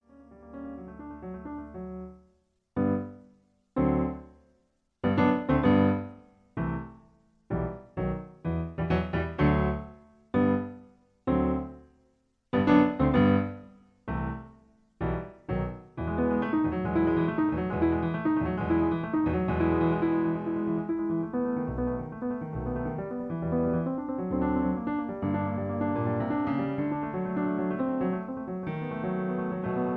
In E. Piano Accompaniment